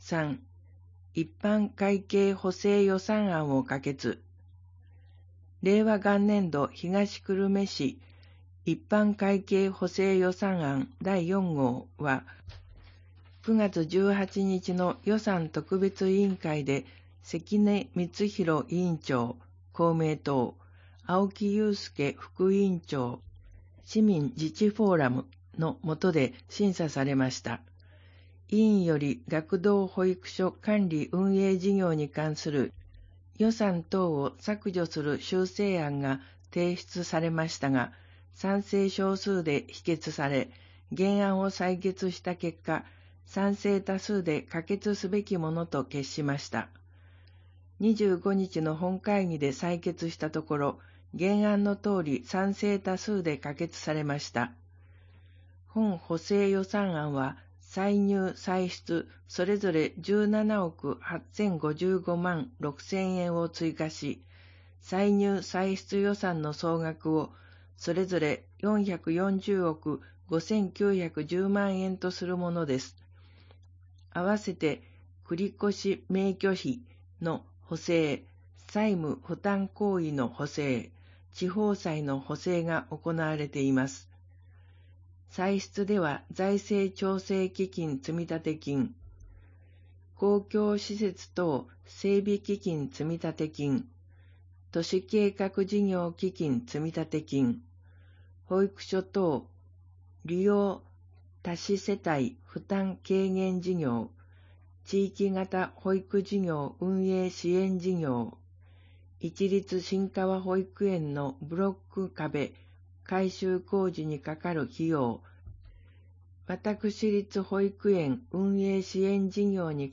声の市議会だより 令和元年11月1日発行 第266号